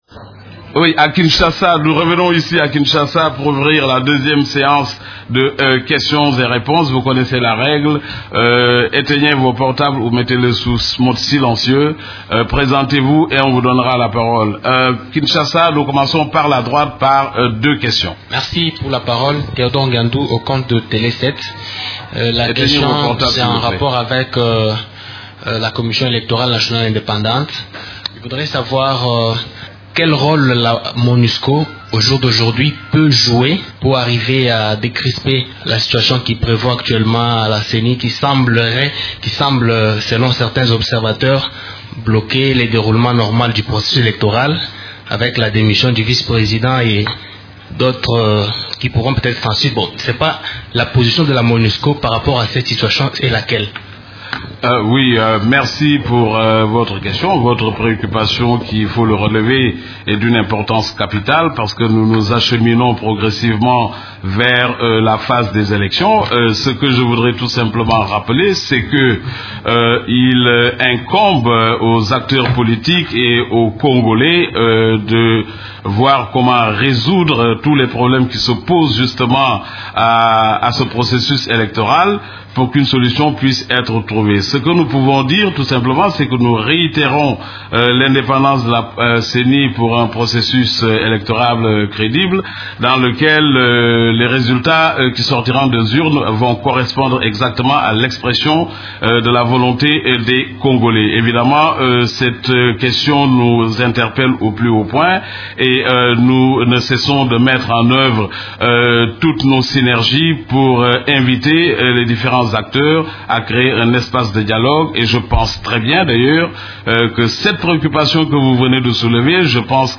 Conférence de presse du 4 novembre 2015
conference_de_presse-00_2.mp3